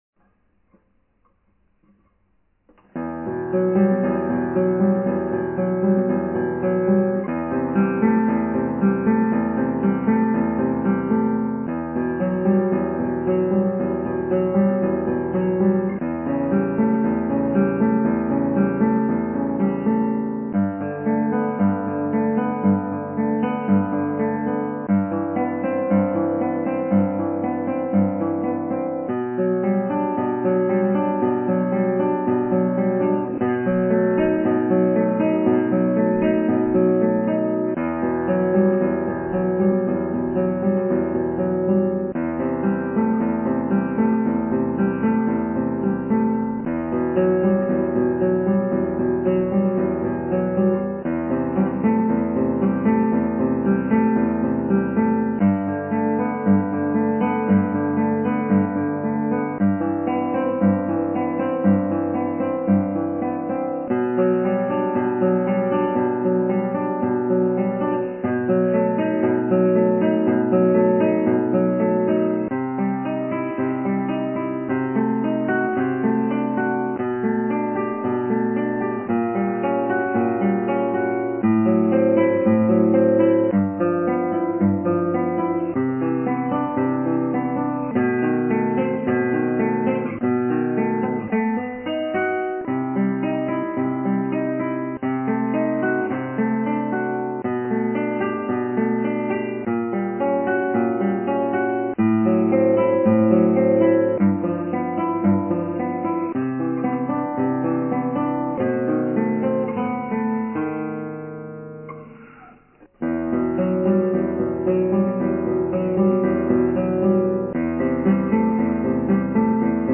アルカンヘルで